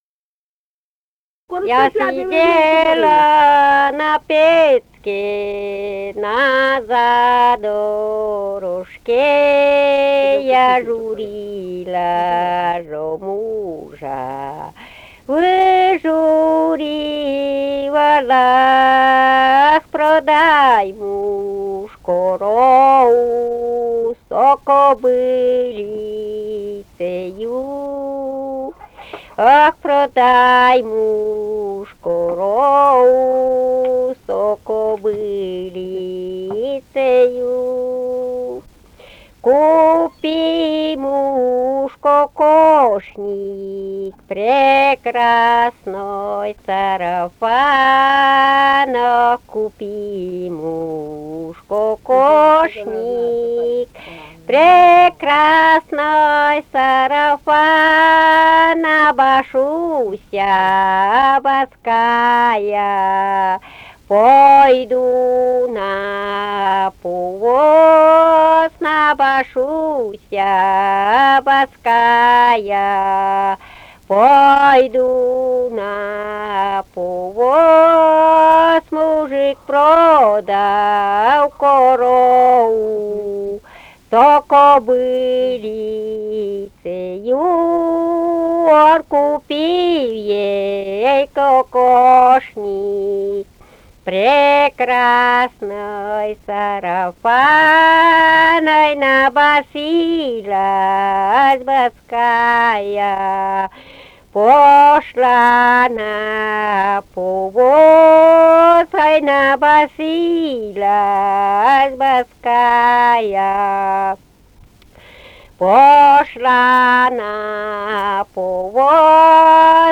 Живые голоса прошлого 128. «Я сидела на печке» (лирическая).